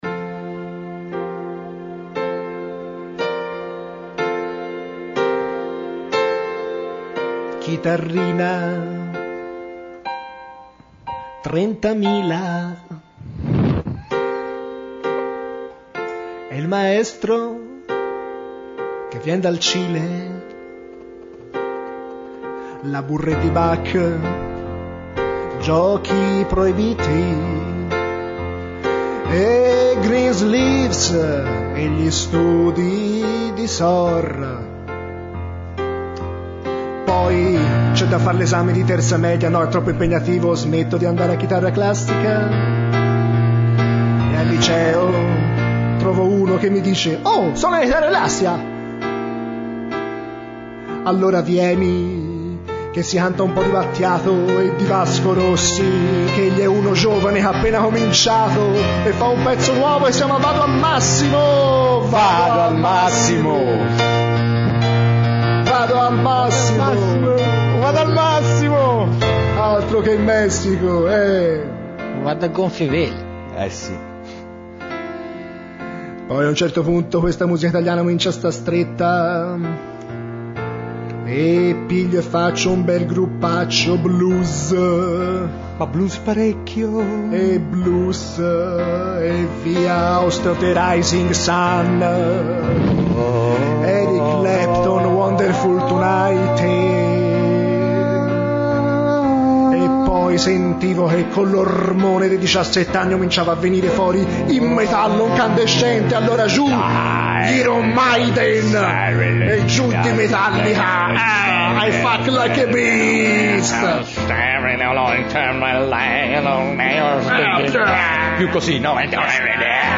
una canzone improvvisata
pianoforte